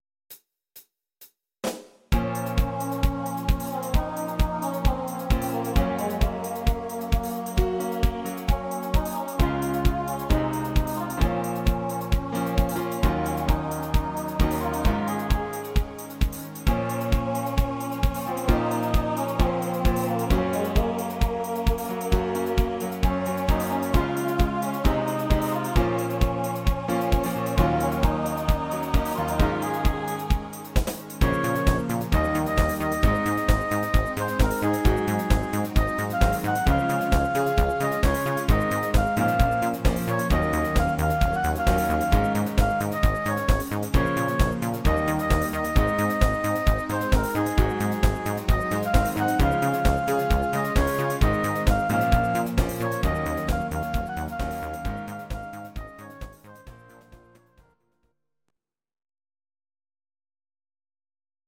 Bbm
Audio Recordings based on Midi-files
Pop, Disco, 1980s